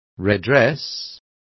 Complete with pronunciation of the translation of redress.